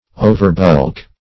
Overbulk \O`ver*bulk"\